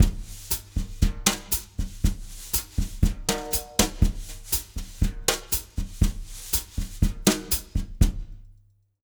120BOSSA03-L.wav